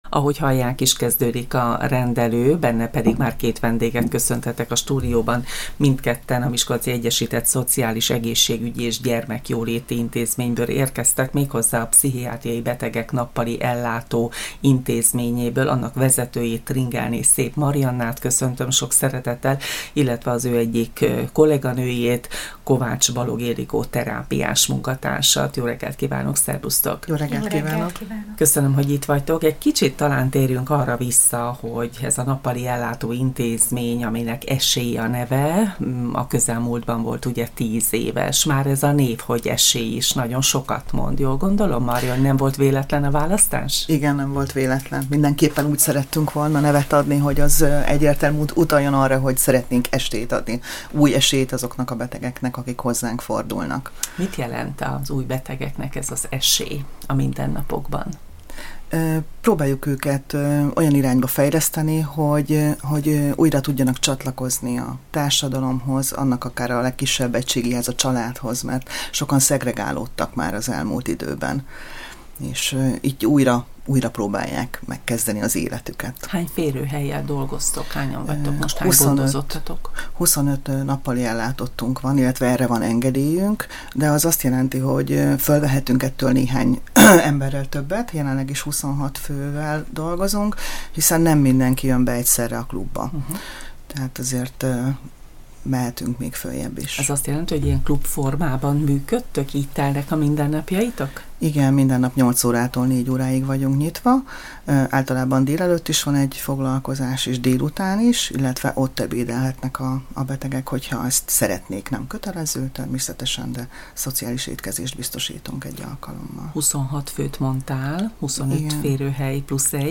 A Miskolci Egyesített Szociális, Egészségügyi és Gyermekjóléti Intézmény munkatársaival beszélgettünk az Esély mindennapjairól.